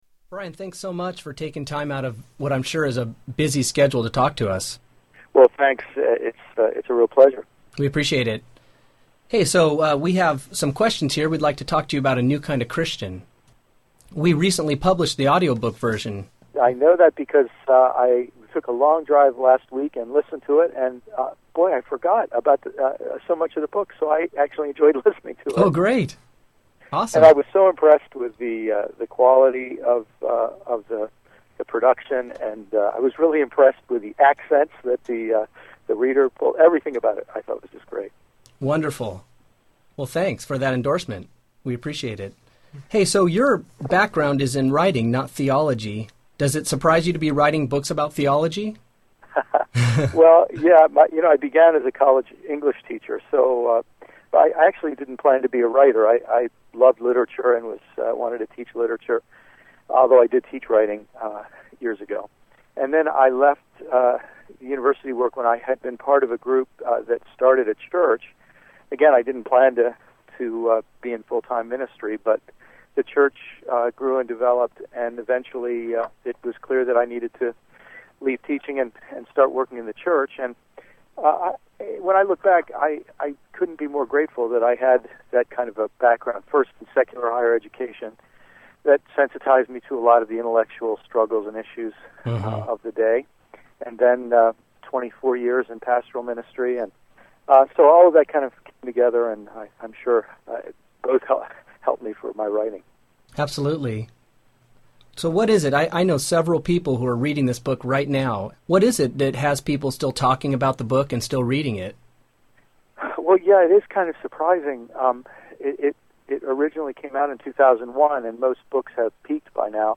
Tags: Media Writer Christian Christian audio books Audio books